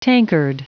Prononciation du mot tankard en anglais (fichier audio)
Prononciation du mot : tankard